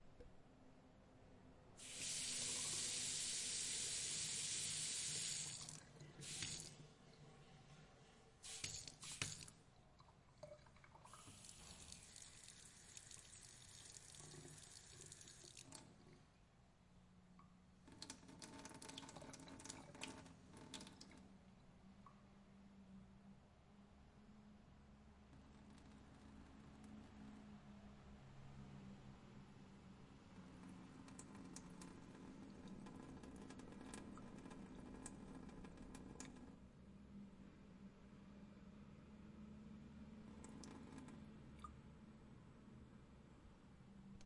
室内和室外的基本声音 " 水槽水 (1)
描述：运行水槽水
Tag: 湿 水槽 跑步 回声 流体 龙头